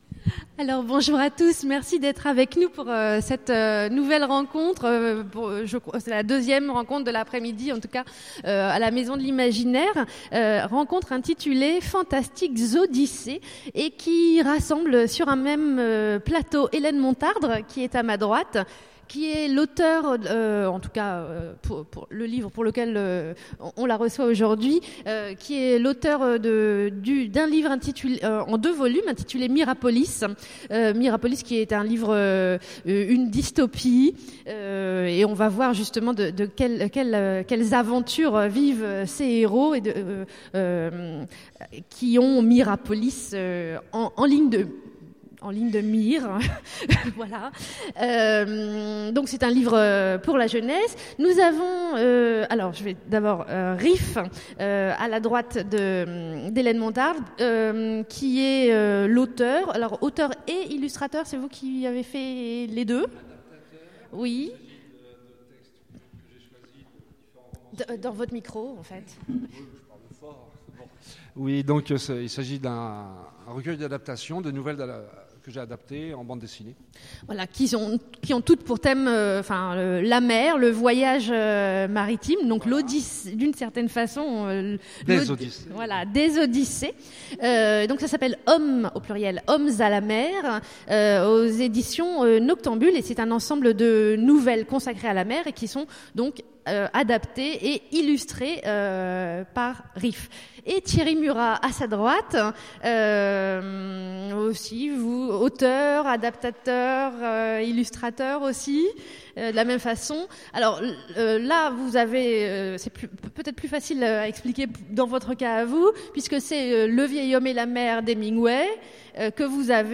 Étonnants Voyageurs 2015 : Conférence Fantastiques odyssées